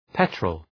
Προφορά
{‘petrəl}